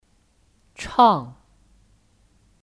唱 (Chàng 唱)